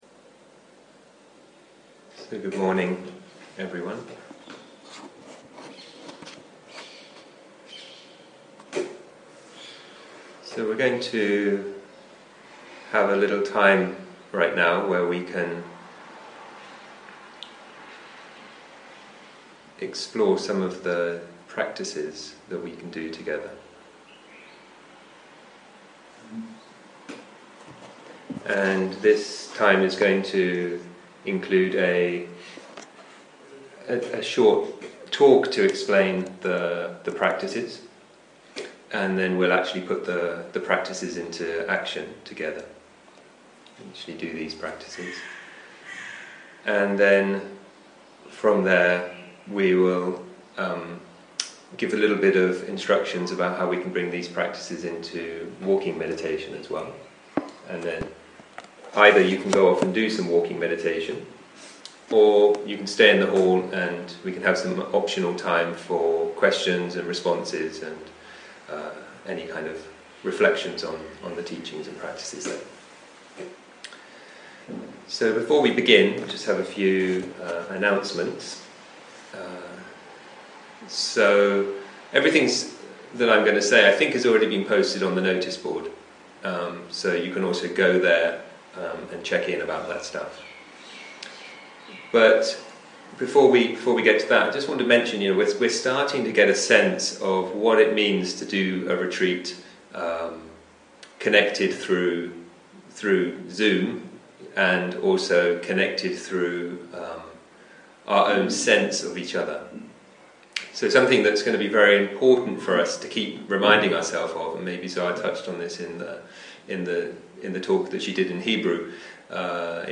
Meditation Instructions: Welcoming Body in a Spacious Awareness 0:00 0:00 סוג ההקלטה: Dharma type: Guided meditation שפת ההקלטה: Dharma talk language: English